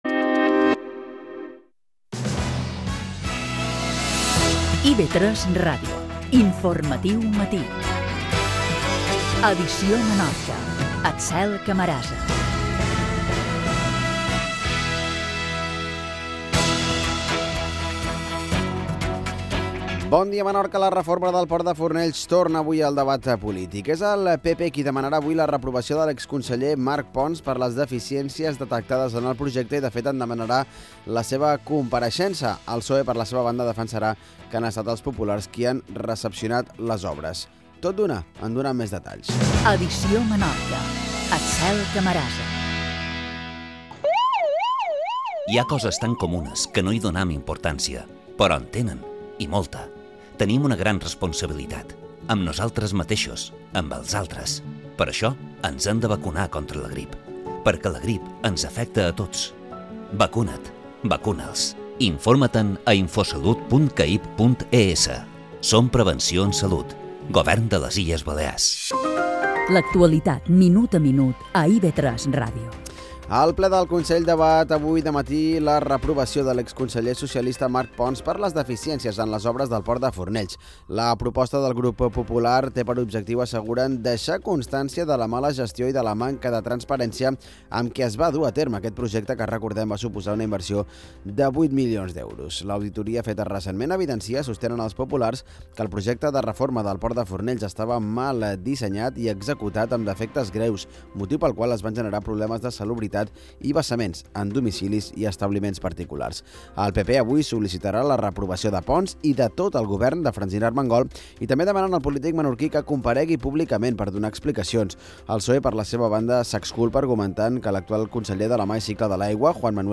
Informatius - Edició Menorca · 2017 · Actualitat Informatiu territorial